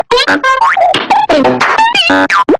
goofyahcartoon.mp3